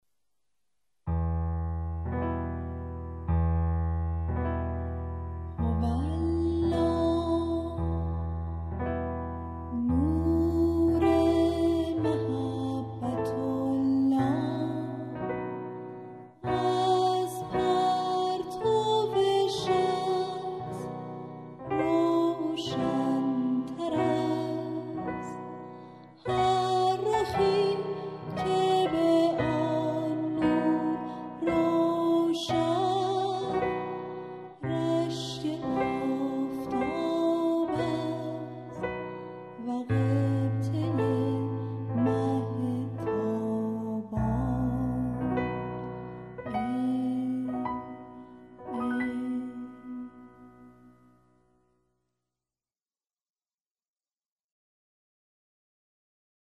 مناجات های صوتی